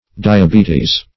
diabetes \di`a*be"tes\, n. [NL., from Gr.